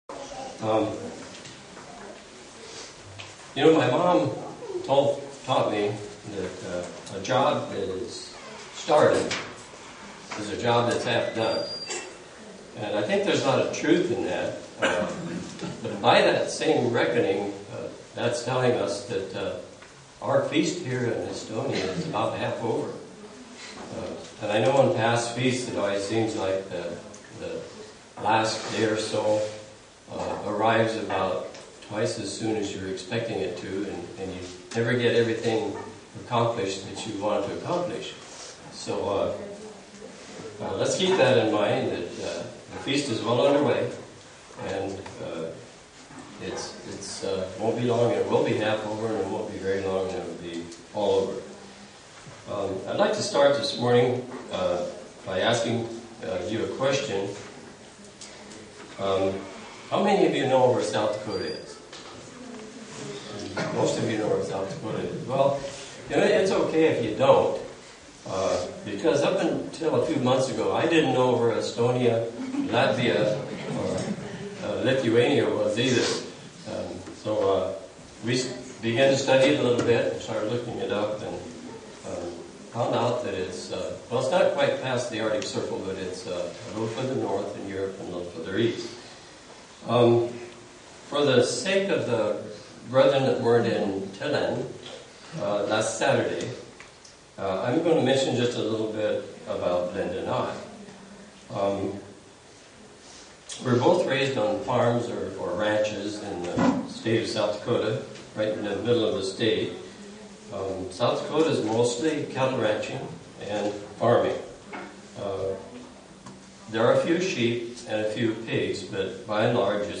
Print Day two of the Feast of Tabernacles in Estonia SEE VIDEO BELOW UCG Sermon Studying the bible?
Given in Buffalo, NY